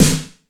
Index of /90_sSampleCDs/Masterbits - Soniq Elements/SPECL FX 9+8/WET SNARES
WET S909  -L 1.wav